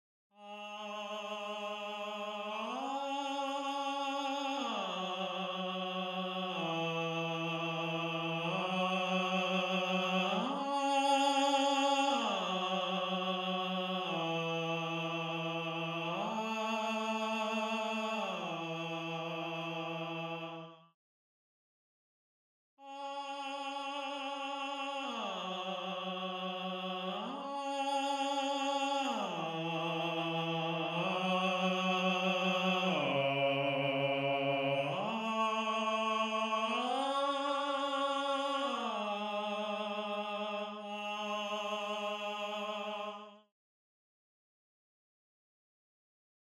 6. Voice 6 (Tenor/Tenor)
gallon-v8sp1-22-Tenor_1.mp3